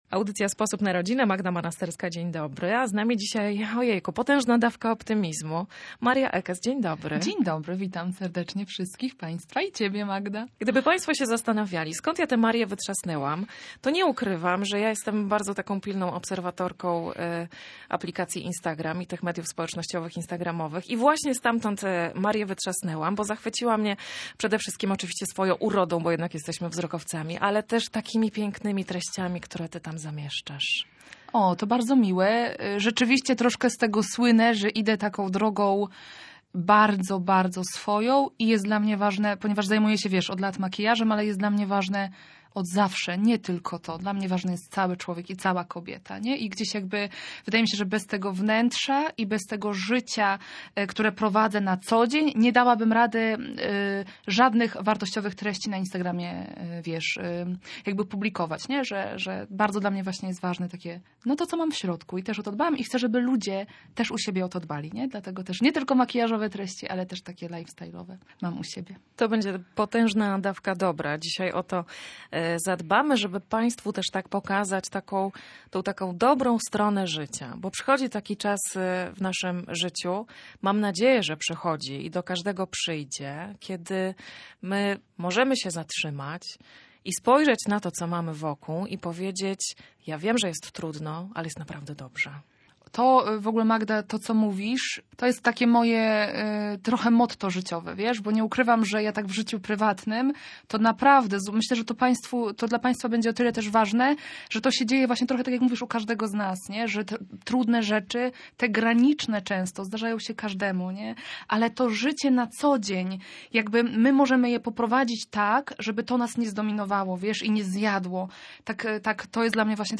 W studiu Radia Gdańsk zasiadły dwie mamy.
To intymna rozmowa o stracie, żałobie i jej przeżywaniu.